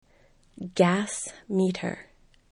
gas (meter)   gæs